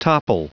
Prononciation du mot topple en anglais (fichier audio)
Prononciation du mot : topple